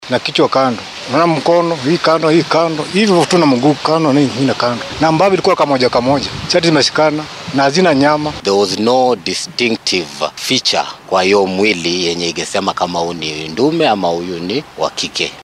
Goobjoogayaasha dhacdadan arkay ayaa warbaahinta u warramay.
Goobjoogayaasha-meydka.mp3